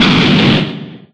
Fire3.ogg